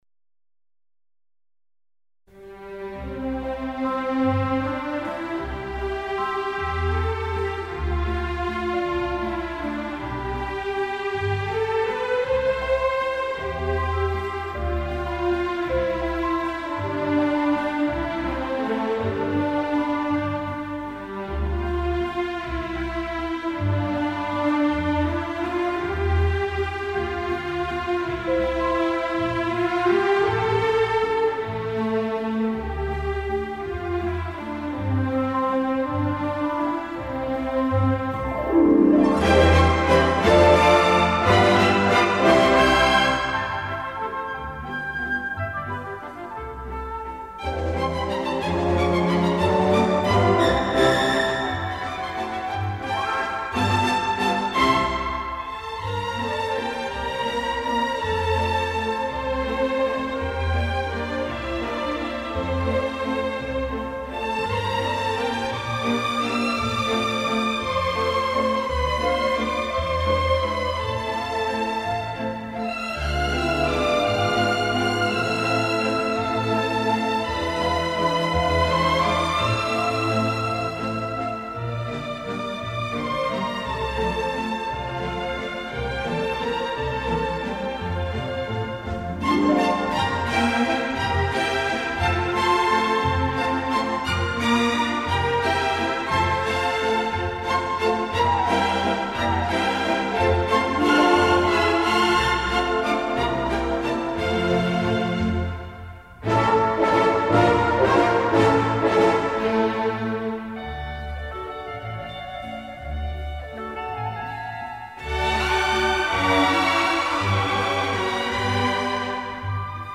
Genre:Classical